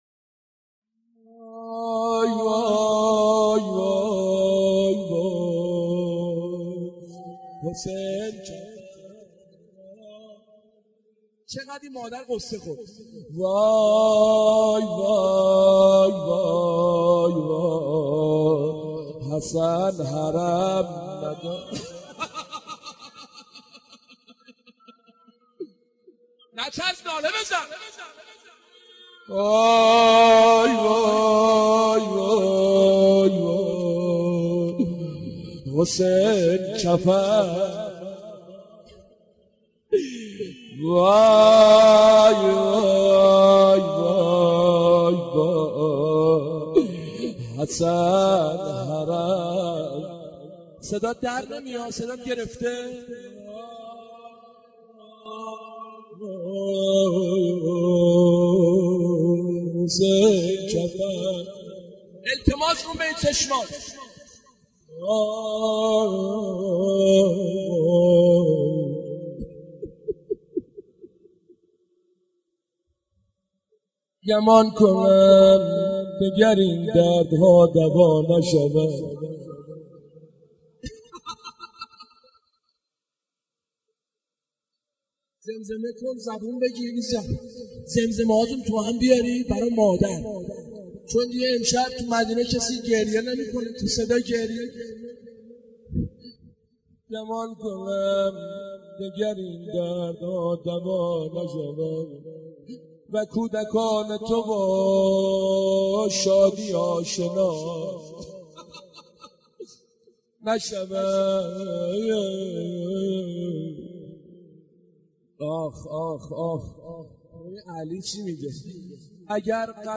دانلود مداحی دوای بی درمان - دانلود ریمیکس و آهنگ جدید